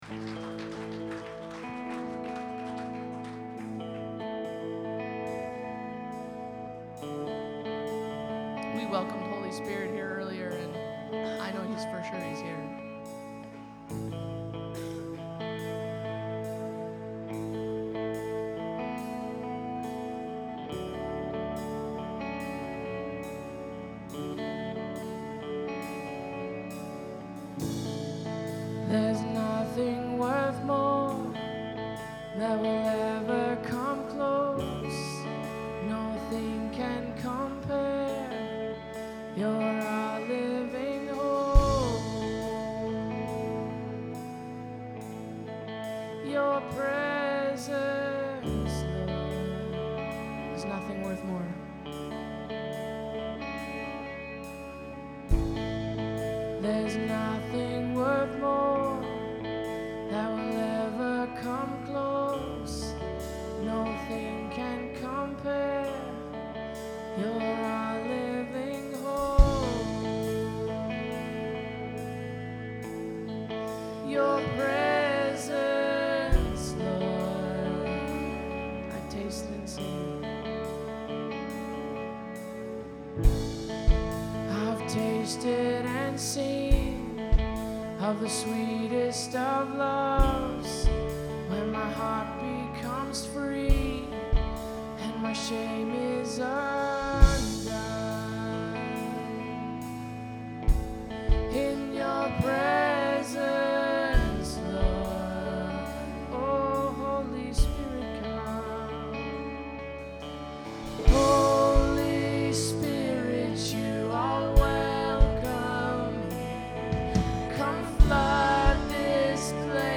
In case you missed it or would just to listen again we’ve decided to share our soundboard recordings from last night’s Engage.
Holy Spirit Kingsway Worship